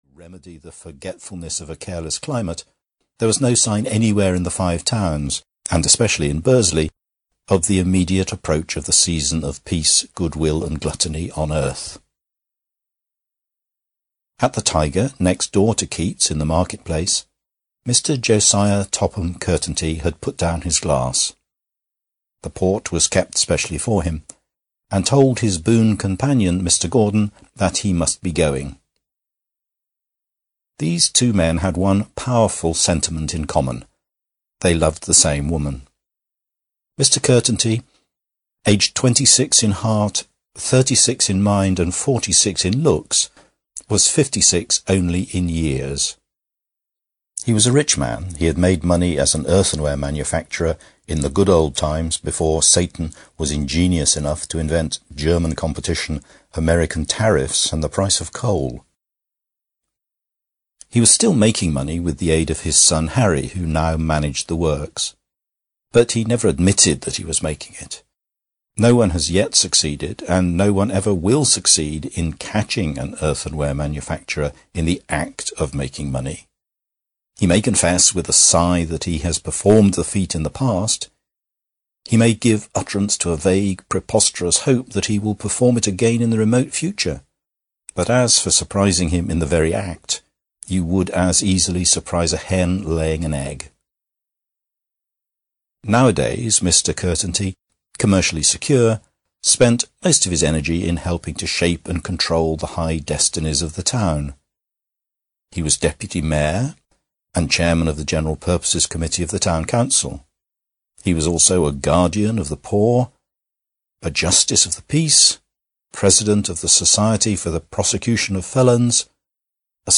Tales of the Five Towns (EN) audiokniha
Ukázka z knihy